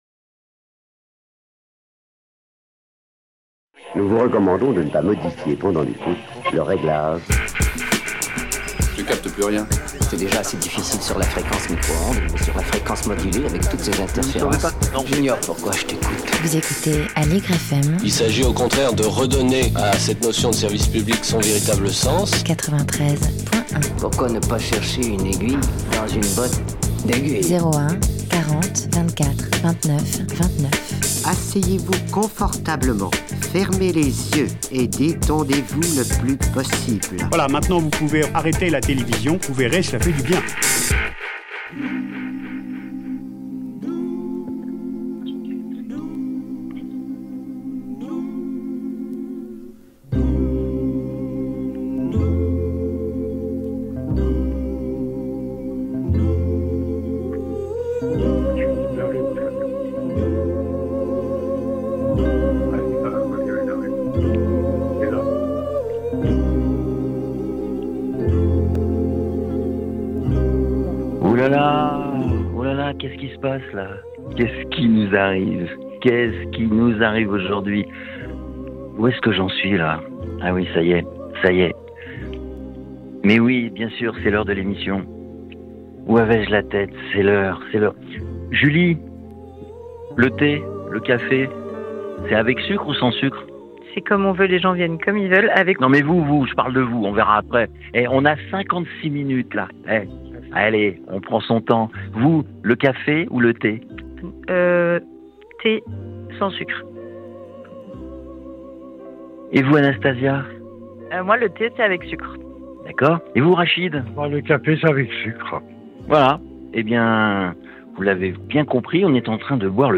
L'etincelle dans la ville est parti à la rencontre de la Maison de la Conversation à l’occasion d’un café solidaire organisé par l’association Entourage. Les représentants de l’association Entourage nous racontent comment ils mettent tout en œuvre pour créer du lien entre riverains et personnes en situation de précarité.